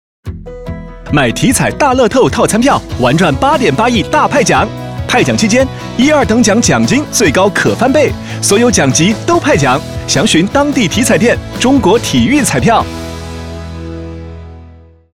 2025体彩大乐透8.8亿派奖遇上套餐票15s- 男版